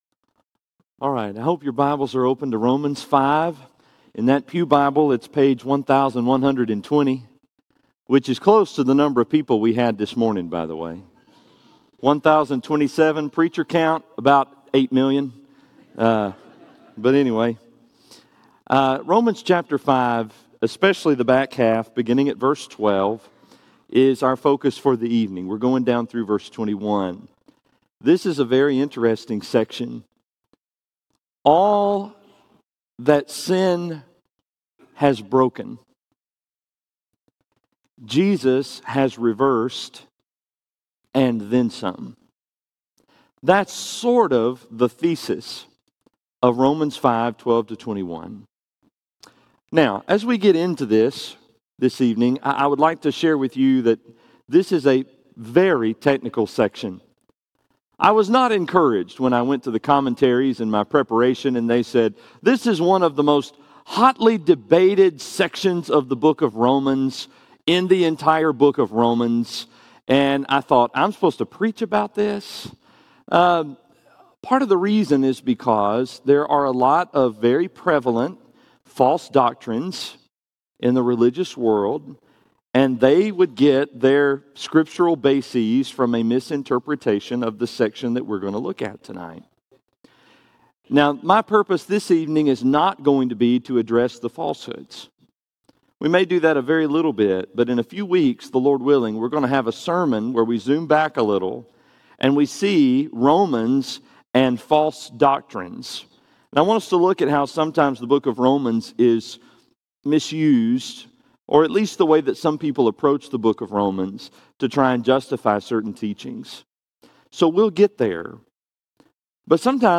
The Free Gift – Henderson, TN Church of Christ